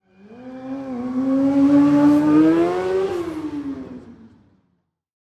Motorcycle Drive-By Fast 1
bike drive-by motorcycle vehicle sound effect free sound royalty free Memes